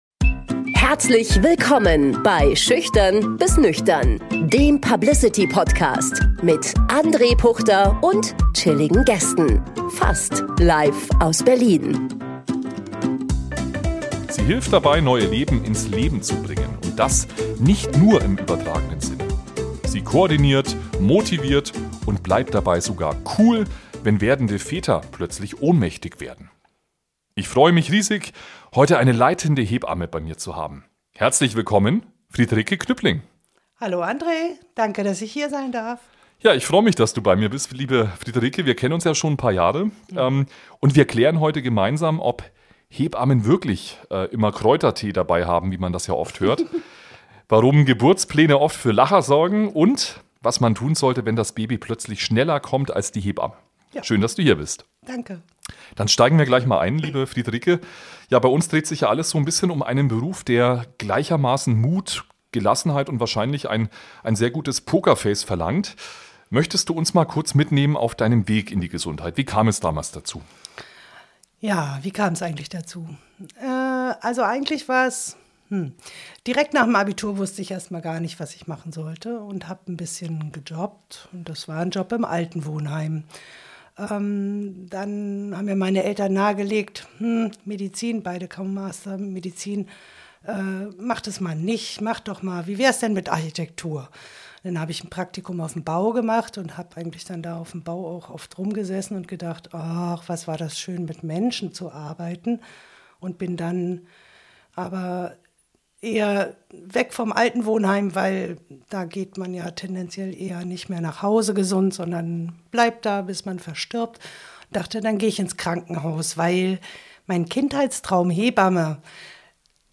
Es wird informativ, emotional und garantiert unterhaltsam!